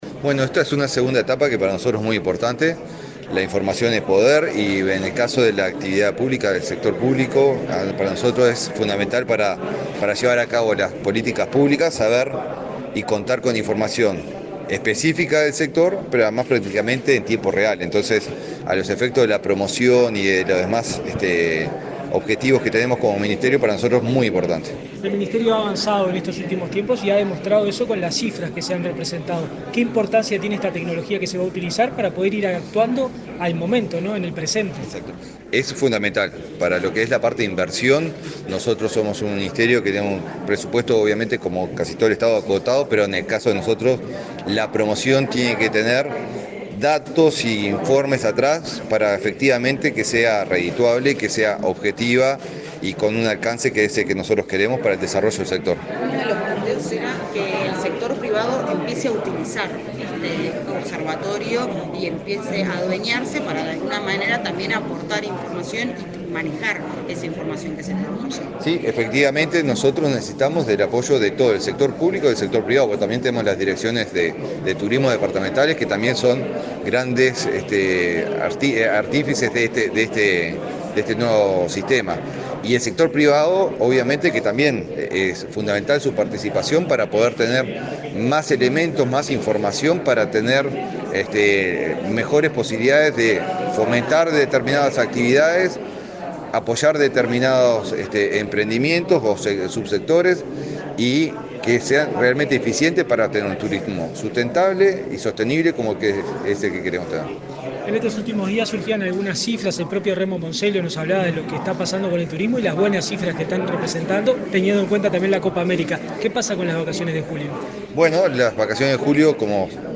Declaraciones del ministro de Turismo, Eduardo Sanguinetti
Declaraciones del ministro de Turismo, Eduardo Sanguinetti 28/06/2024 Compartir Facebook X Copiar enlace WhatsApp LinkedIn El Ministerio de Turismo y Antel presentaron, este viernes 28 en Montevideo, los principales logros de la segunda etapa del proyecto Observatorio de Turismo Inteligente. El titular de la referida cartera, Eduardo Sanguinetti, dialogó con la prensa acerca de la importancia para el sector.